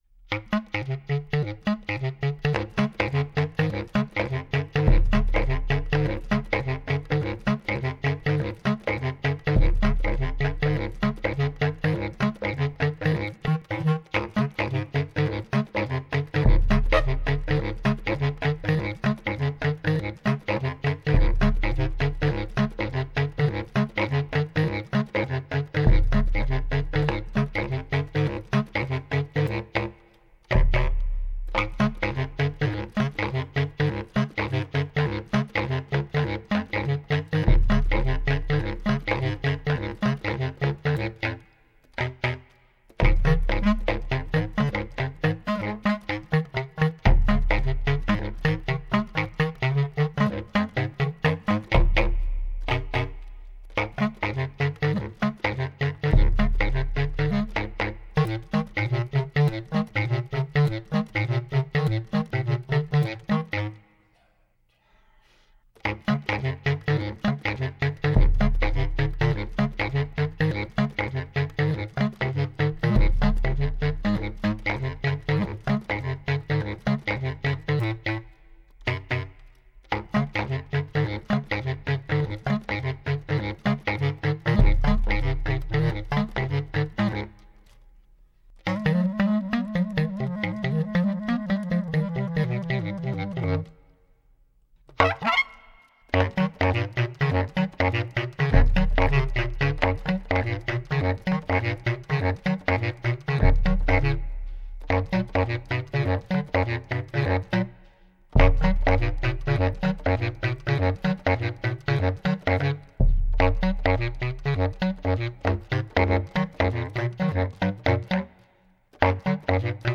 Recorded at home in Manhattan September 22 & 30, 2012
alto and bass clarinets, bass drum
Stereo (Pro Tools)